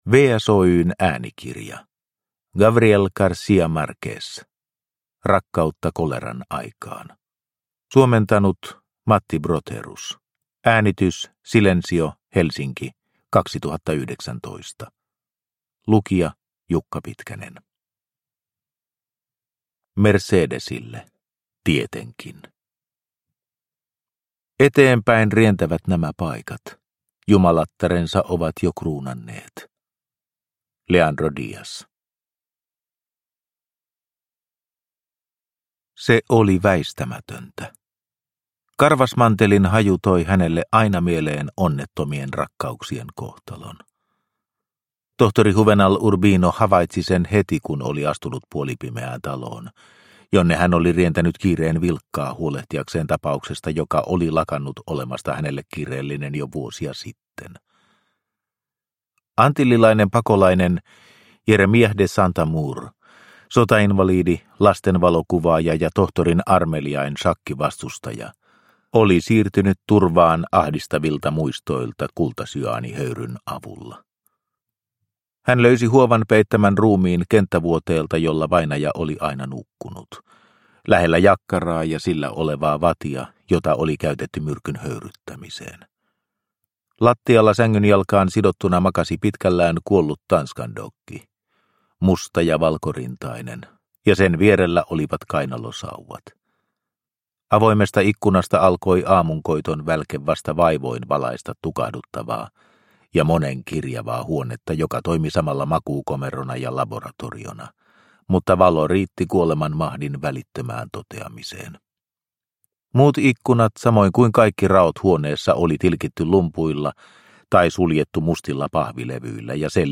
Rakkautta koleran aikaan – Ljudbok – Laddas ner